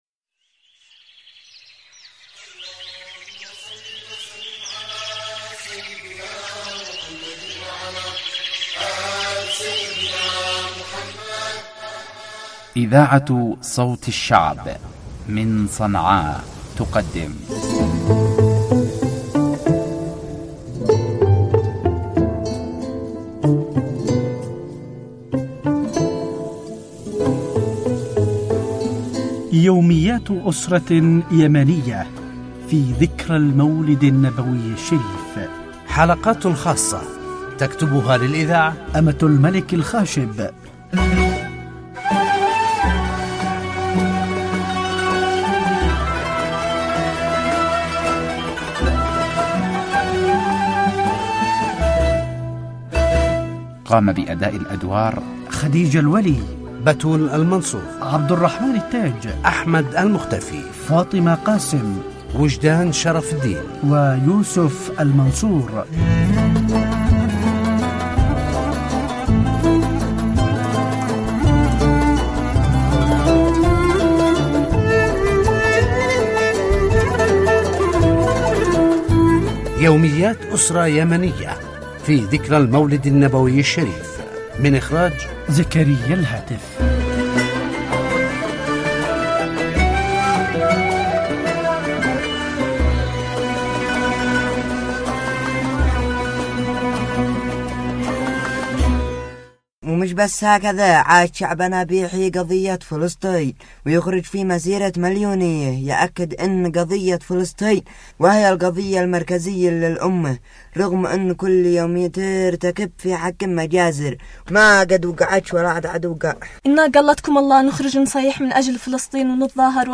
مسلسل يوميات إسره يمنيه_الحلقة ( 05 )